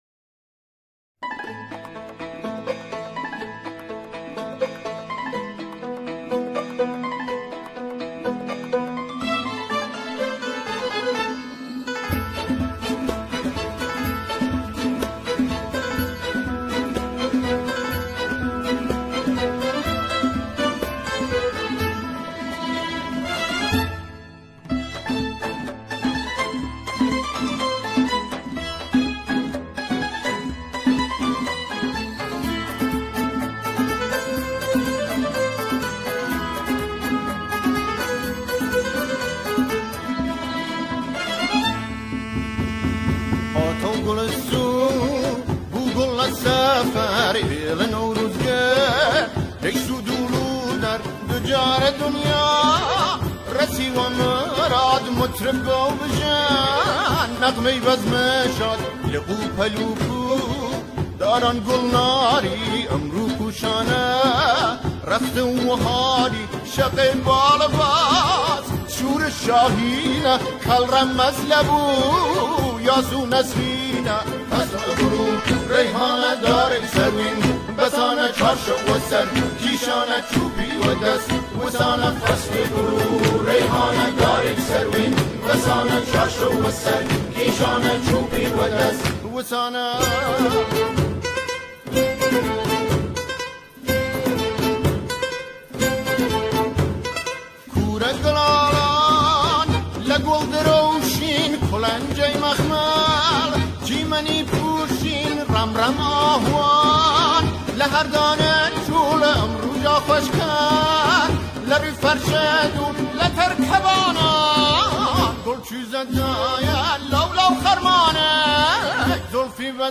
همراهی گروهی از جمعخوانان